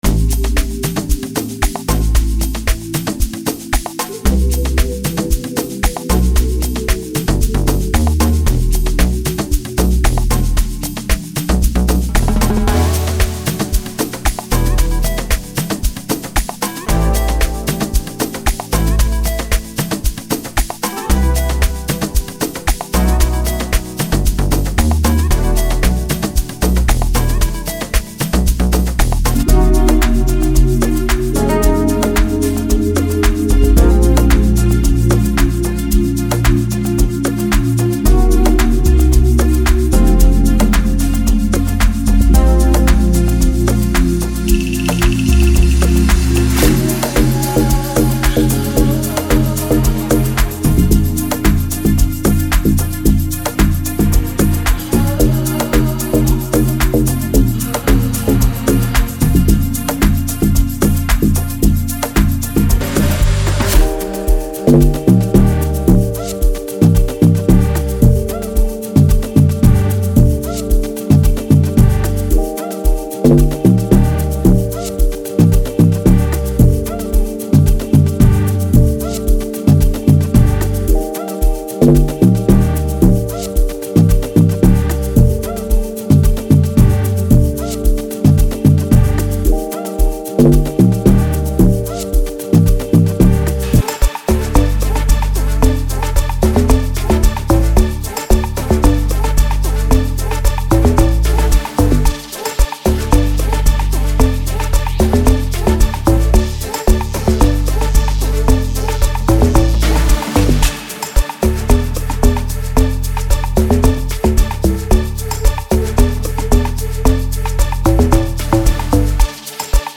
• 115 WAV Loops (Including Drums, Melodics, Vocals, and FXs)